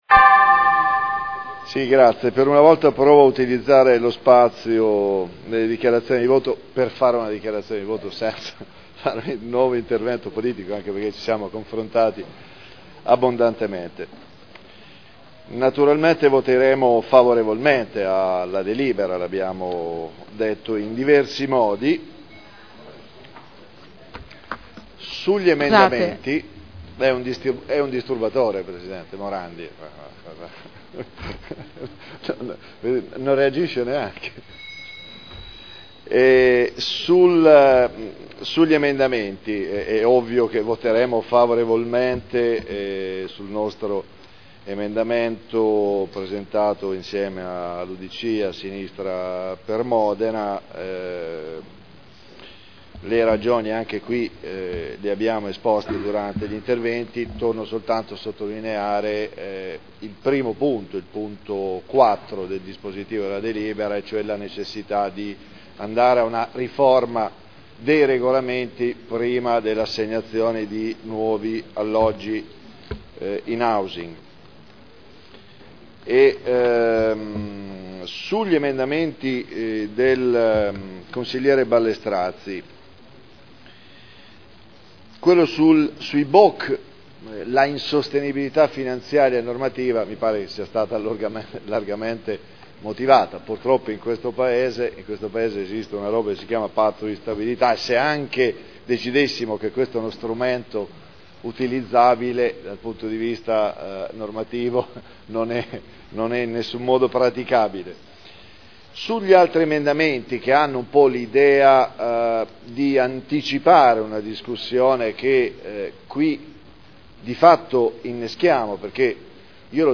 Seduta del 19 marzo Proposta di deliberazione Programma per l’edilizia sociale – Principi ed indirizzi – Per un nuovo piano abitativo sociale (Qualità – Sostenibilità – Equità) Dichiarazioni di voto